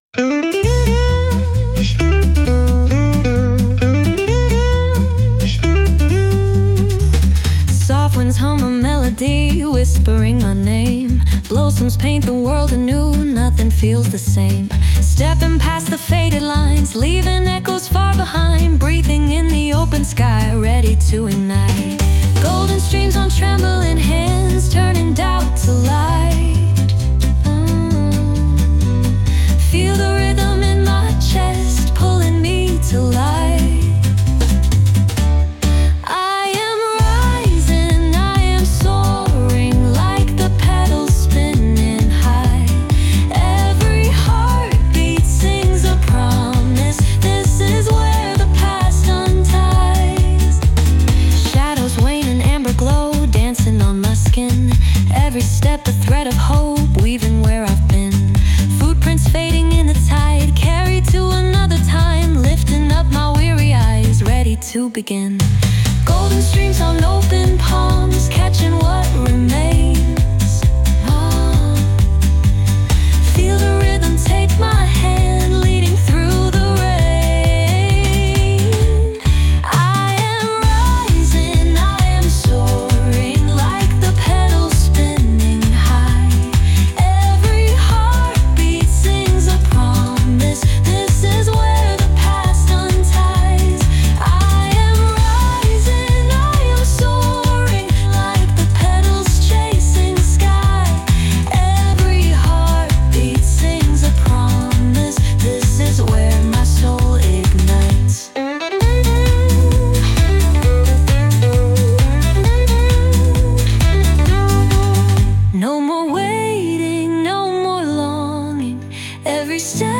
洋楽女性ボーカル著作権フリーBGM ボーカル
著作権フリーオリジナルBGMです。
女性ボーカル（洋楽・英語）曲です。
新しい出発をテーマに、ジャズ風アップテンポでおしゃれな曲にしあげました✨